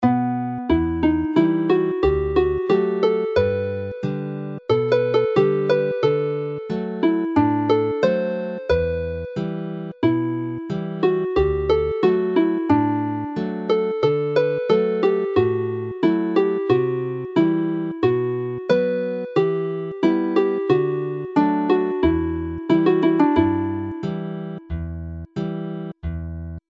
Santiano - lively
Play the tune slowly